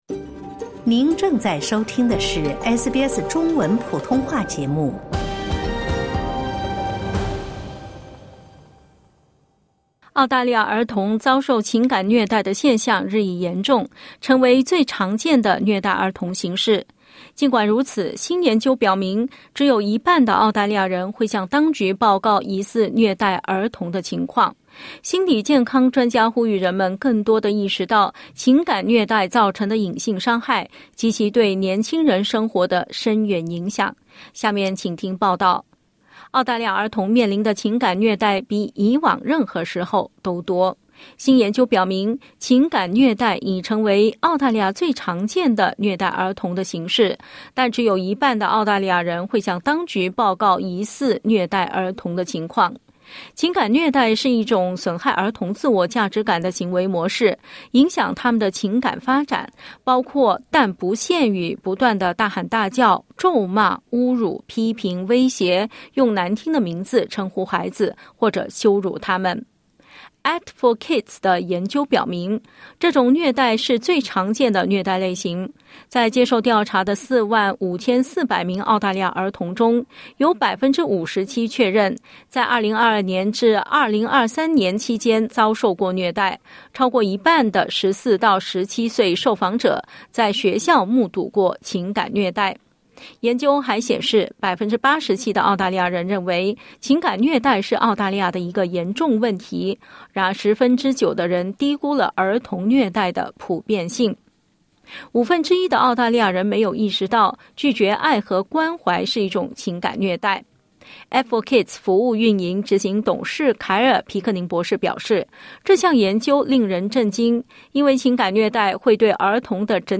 澳大利亚儿童遭受情感虐待的现象日益严重，成为最常见的虐待儿童形式。 (点击音频收听详细采访)